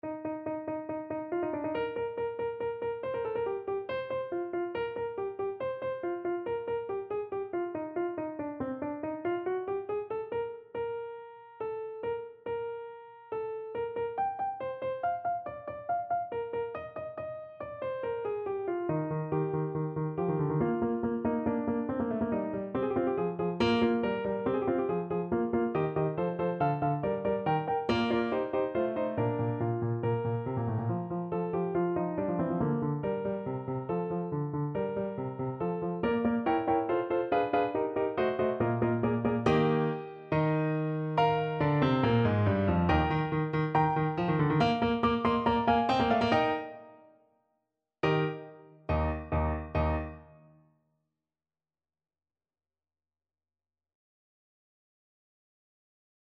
Play (or use space bar on your keyboard) Pause Music Playalong - Piano Accompaniment Playalong Band Accompaniment not yet available reset tempo print settings full screen
Eb major (Sounding Pitch) F major (Clarinet in Bb) (View more Eb major Music for Clarinet )
= 140 Allegro (View more music marked Allegro)
4/4 (View more 4/4 Music)
Classical (View more Classical Clarinet Music)